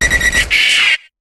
Cri de Doduo dans Pokémon HOME.